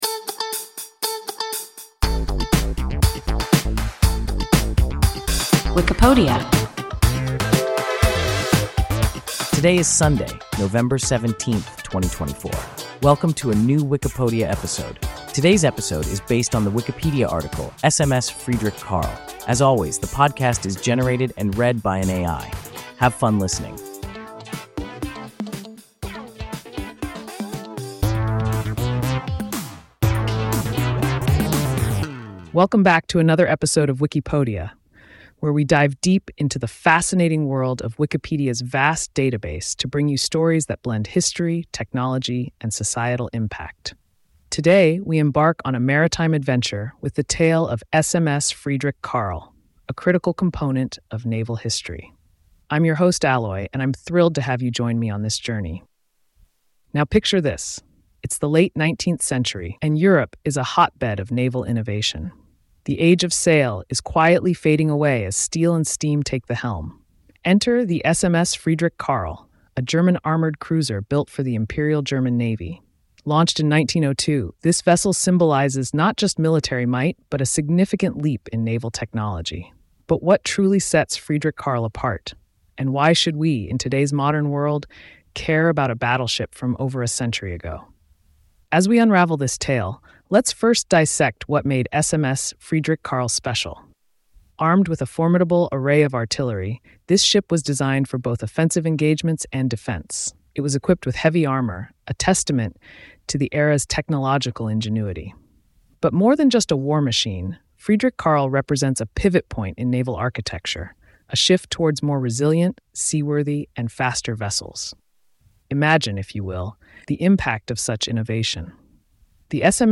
SMS Friedrich Carl – WIKIPODIA – ein KI Podcast